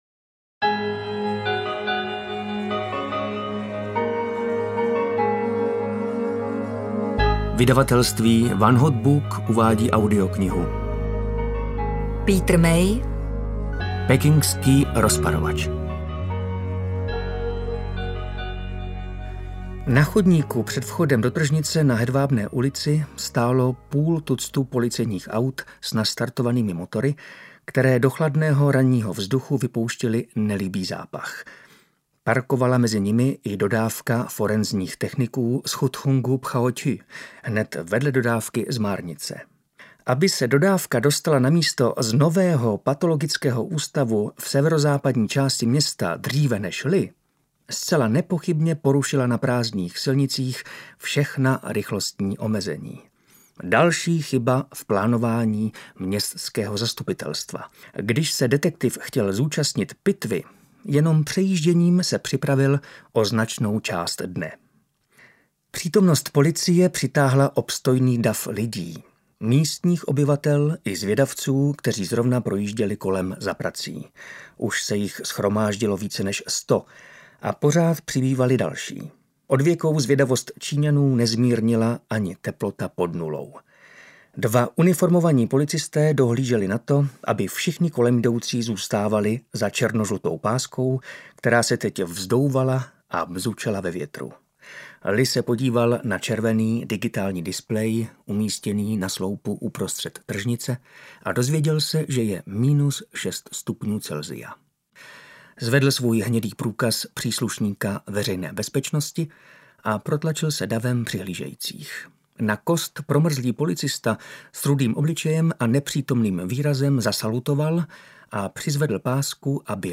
Pekingský rozparovač audiokniha
Ukázka z knihy